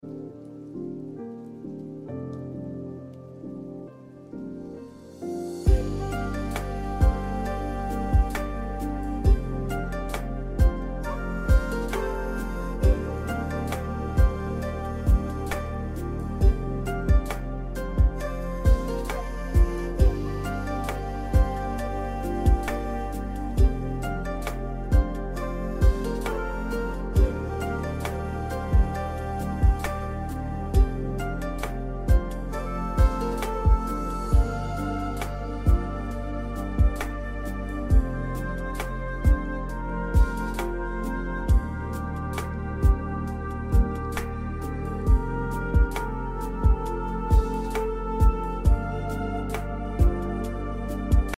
Japanese Lofi HipHop